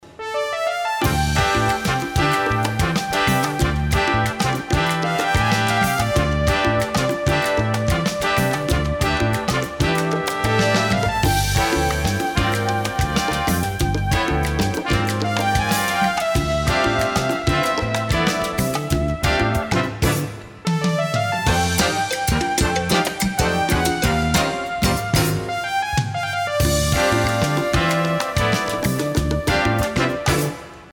Category: little big band
Style: mambo
Solos: open